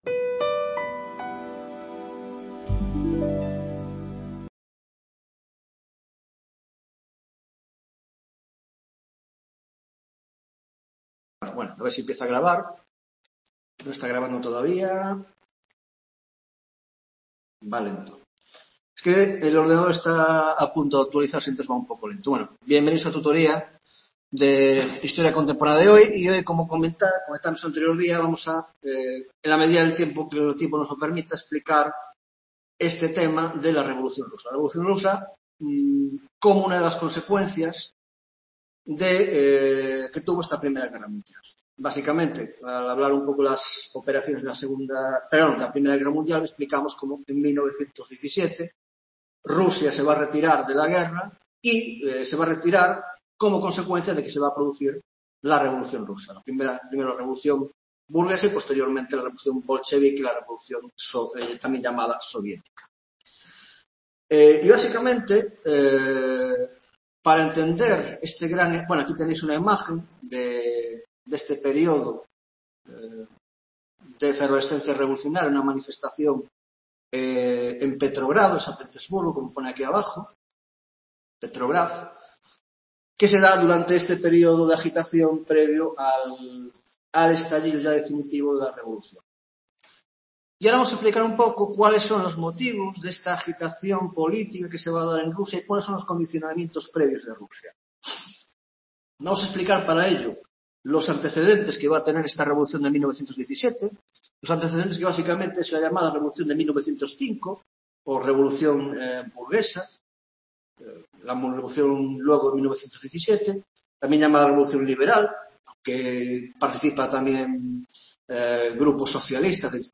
14ªTutoría de Historia Contemporánea - La Revolución Rusa (1917) (1ª parte): 1) Antecedentes situación de la Rusia prerrevolucionaria; 2) La Revolución burguesa de 1905; 3) La Crisis de la Rusia zarista durante la I Guerra Mundial; 4) Revolución Liberal de Febrero de 1917 y el "Gobierno Provisional"